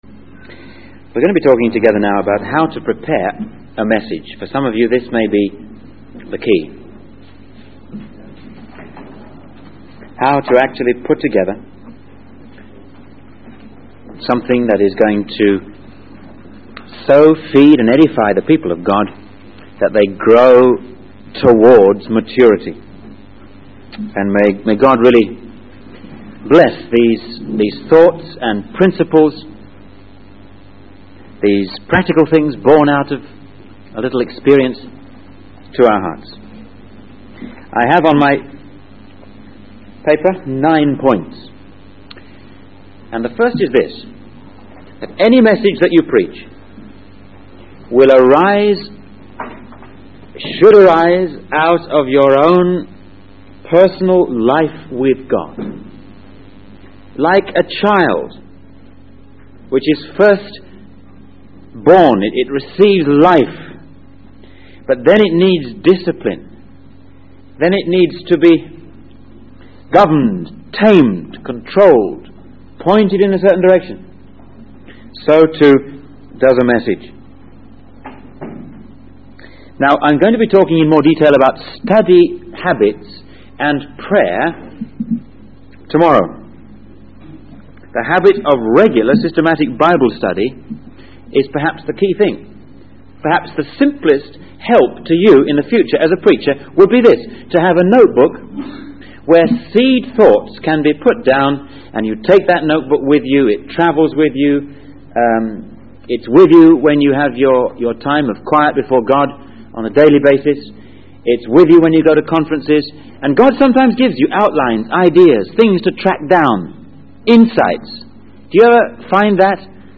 In this sermon, the speaker shares his experience of preaching in a convention in India.